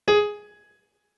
MIDI-Synthesizer/Project/Piano/48.ogg at 51c16a17ac42a0203ee77c8c68e83996ce3f6132